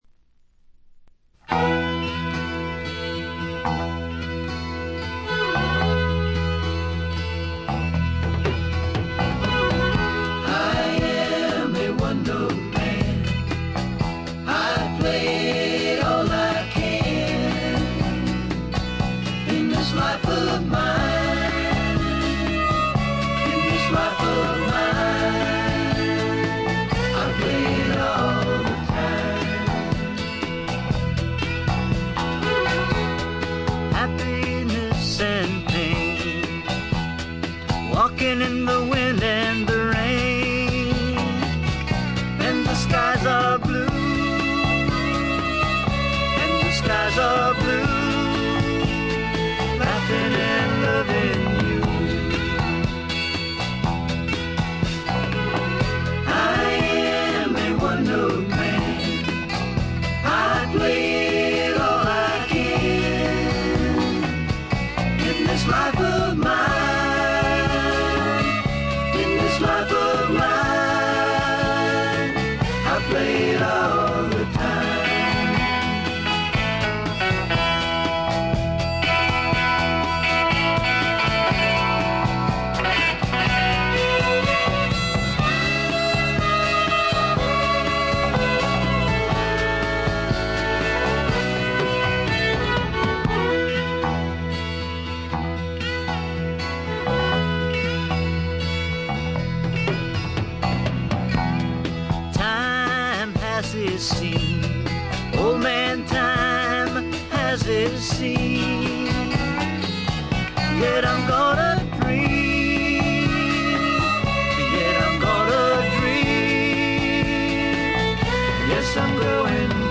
ホーム > レコード：カントリーロック
これ以外は軽微なバックグラウンドノイズ少々、軽微なチリプチ少々。
試聴曲は現品からの取り込み音源です。